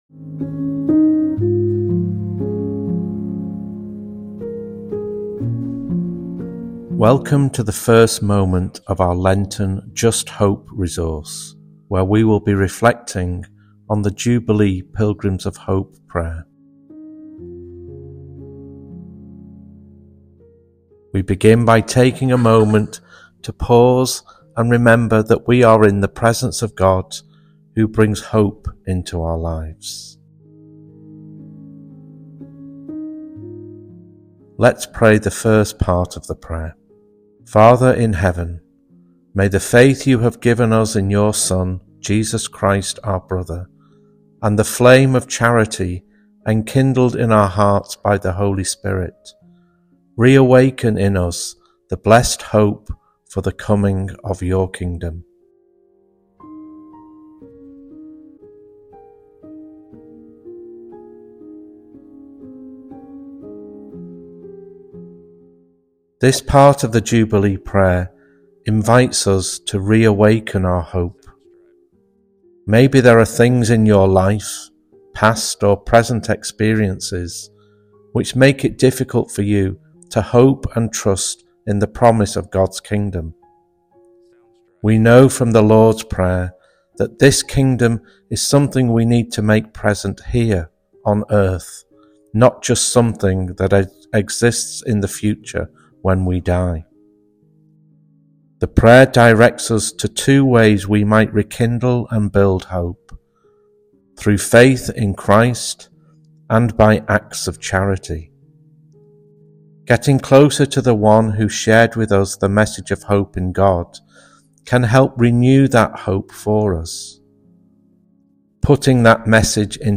A moment of guided reflection
A moment of intercessory prayer